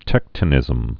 (tĕktə-nĭzəm)